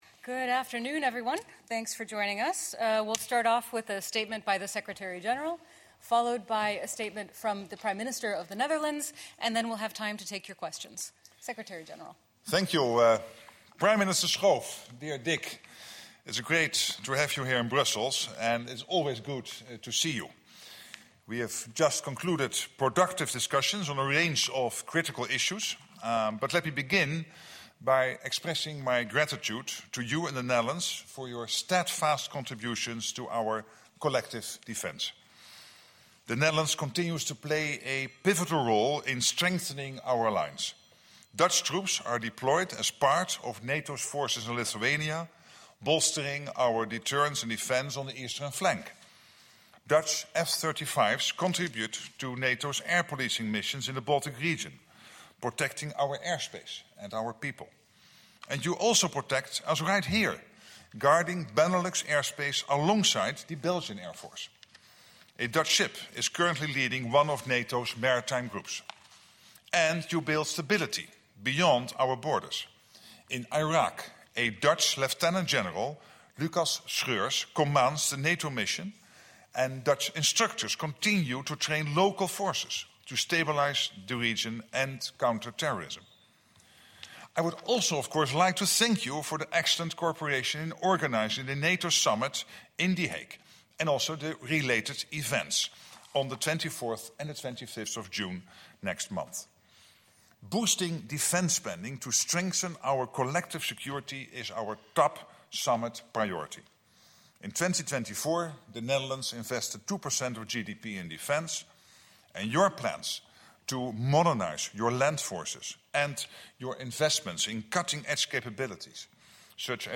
Joint press conference with NATO Secretary General Mark Rutte and the Prime Minister of the Netherlands, Dick Schoof